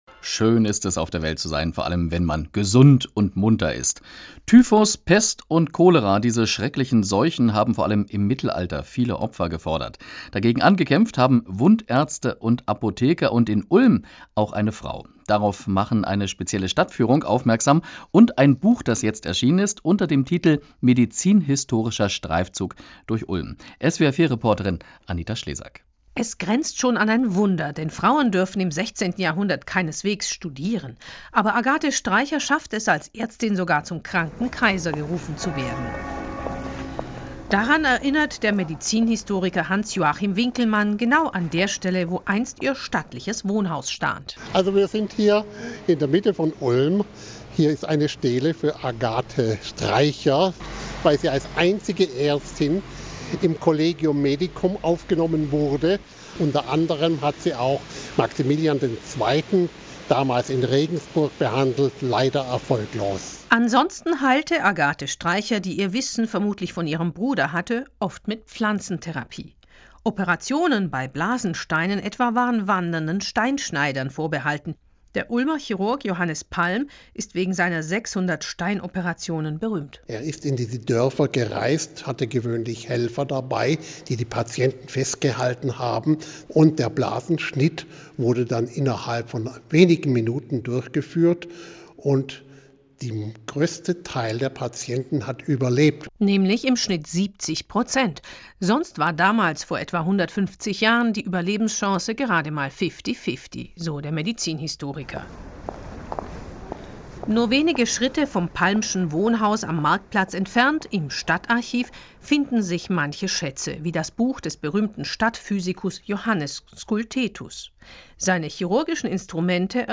Medizinhistorischer Streifzug im "Schwabenradio" - Mitschnitt SWR-Studio Ulm